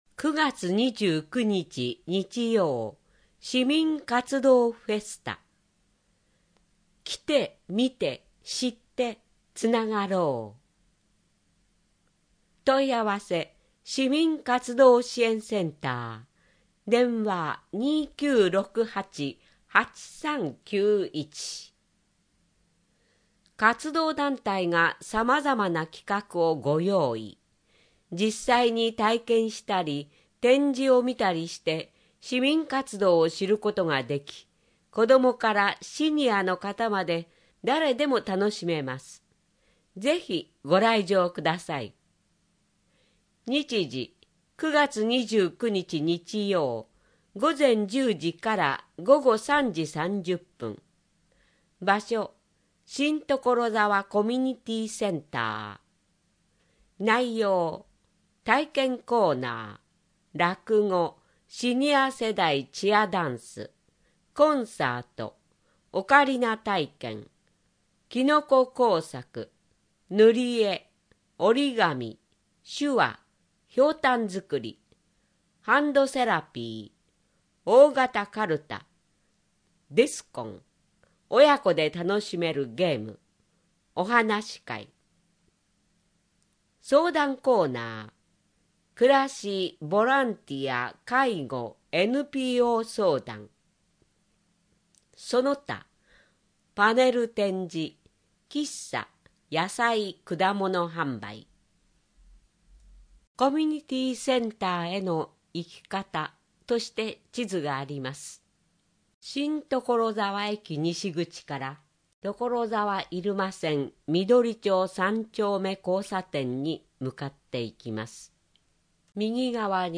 【音声版】広報ところざわ令和6年9月号